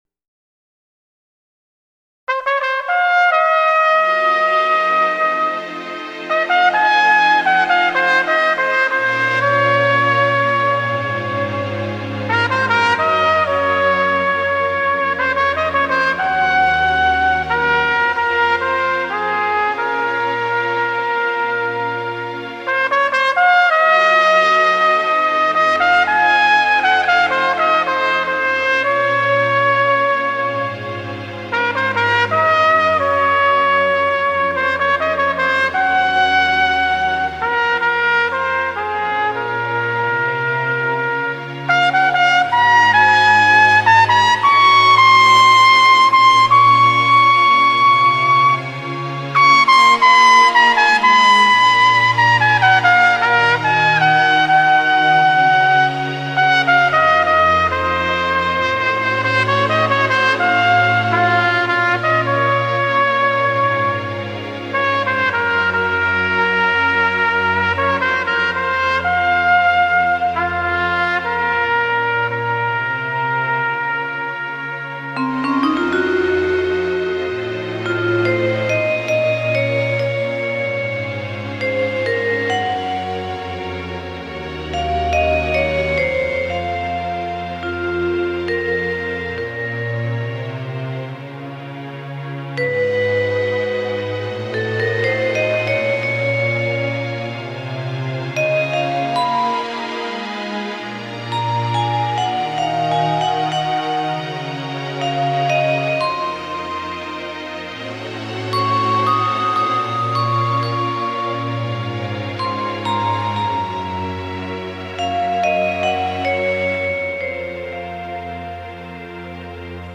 Trumpet Sounds of My Students:
They are known for big trumpet sounds.